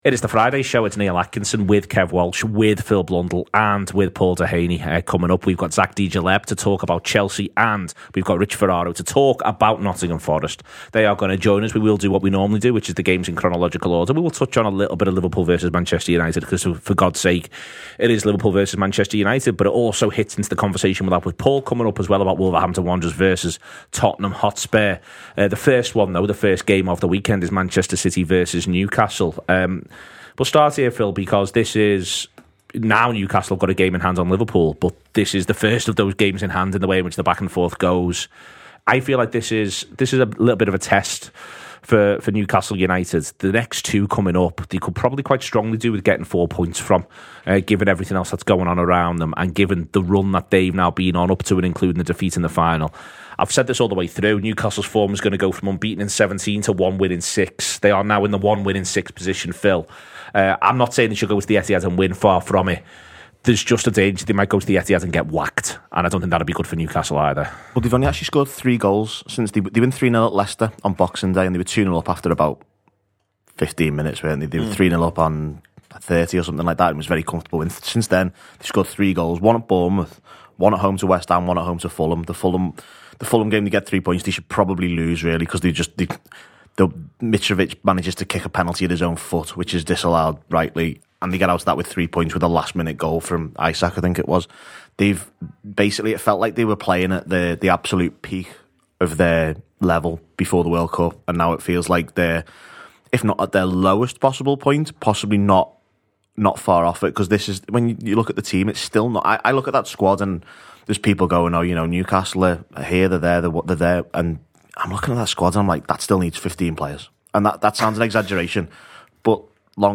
Fans preview the weekend’s Premier League action, with Chelsea looking set for an uncertain future under Graham Potter.